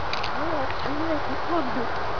EVP (Electronic Voice Phenomena)
Some examples of EVP recordings (in wav format)